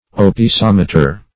Search Result for " opisometer" : The Collaborative International Dictionary of English v.0.48: Opisometer \Op`i*som"e*ter\, n. [Gr.